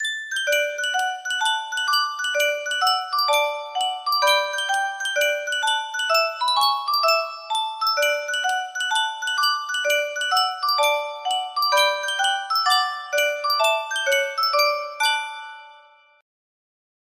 Yunsheng Music Box - McNamara's Band 4217 music box melody
Full range 60